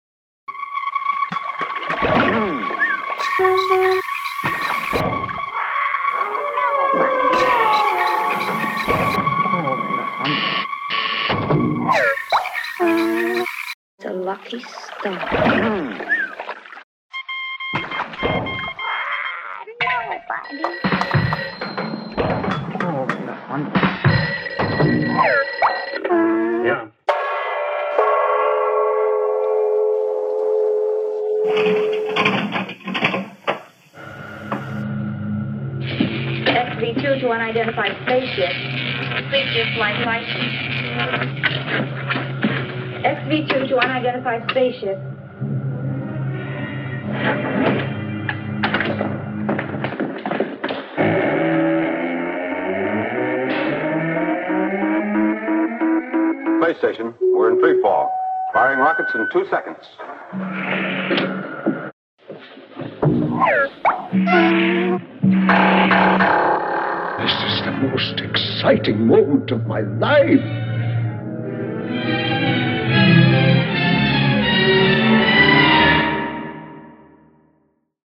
Samples include voice/spoken word/dialogs, sfx/foley and music.
Second official (parody) advertisement